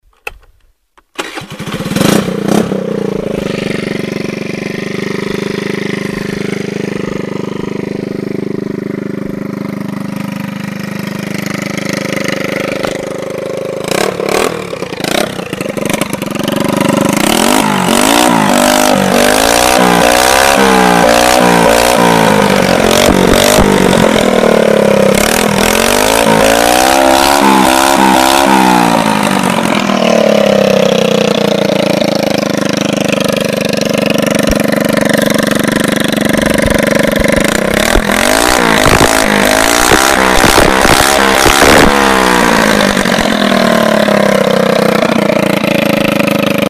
Звуки мопеда, скутера